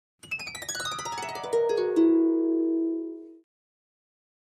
Harp, Descending Gliss, 7th Chord, Type 2 - Short Sustain